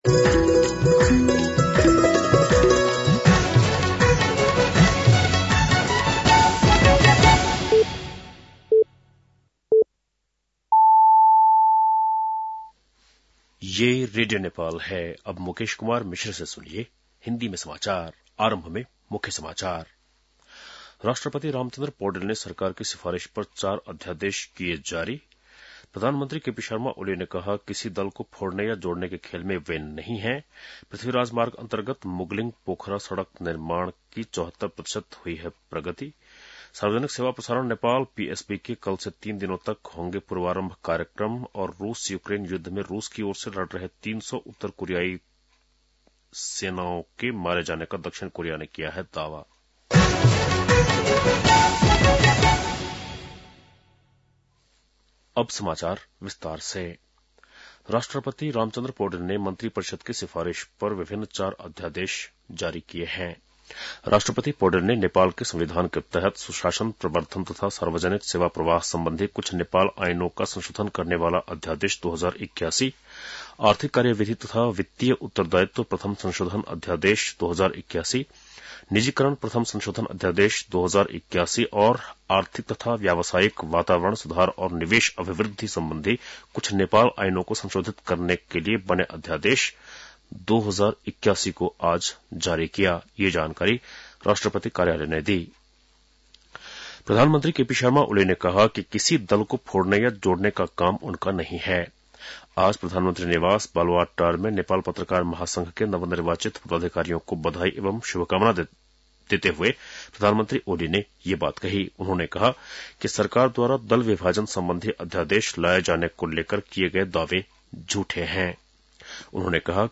बेलुकी १० बजेको हिन्दी समाचार : १ माघ , २०८१